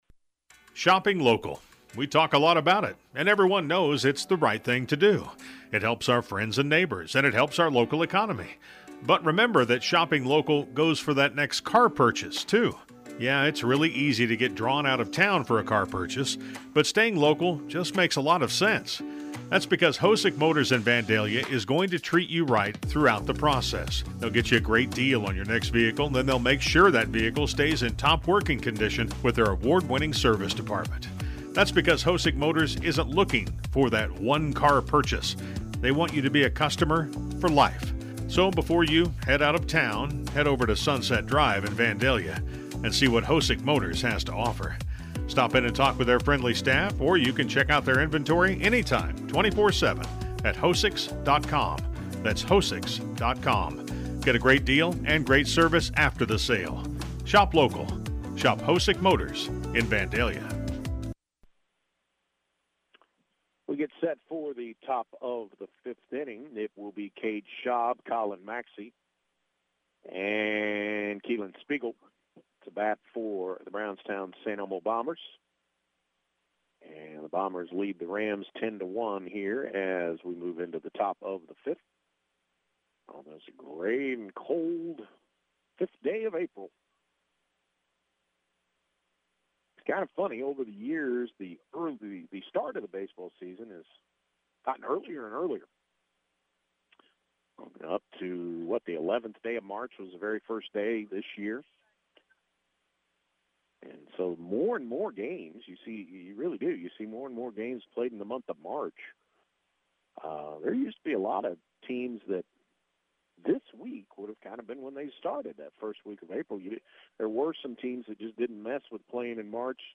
Full Game Audio-BSE vs. Ramsey baseball